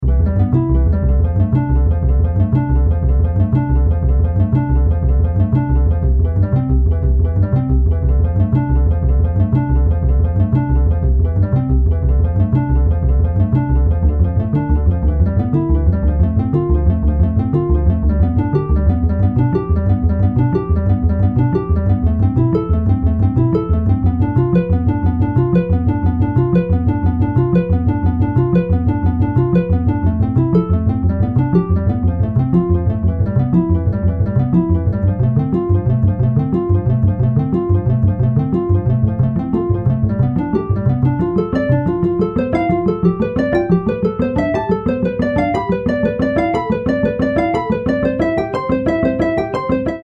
TwoTone is a tool to make a sonification out of a dataset. For experimental purposes, I choose a dataset with data about abstract subjects: US Historical Revenue, Public Debt and GDP.